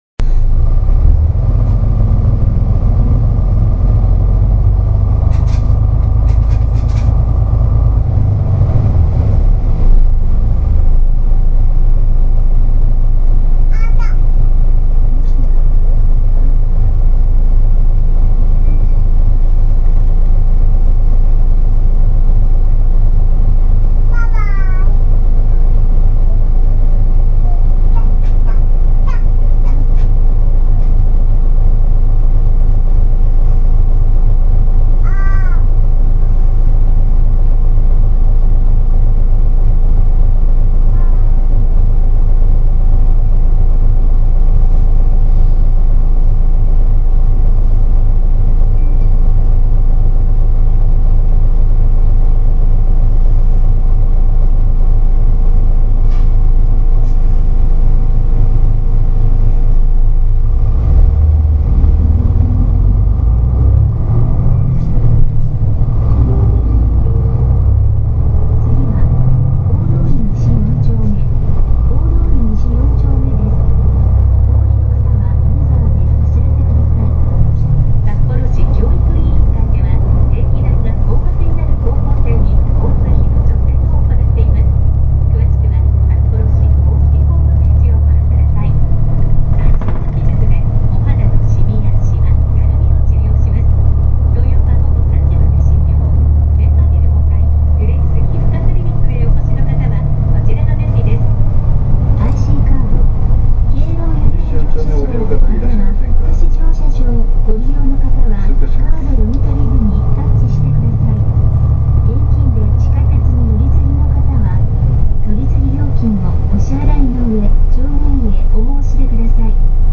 車内放送(最新)     ドアが閉まった際に「発車致します、ご注意下さい」と流れます。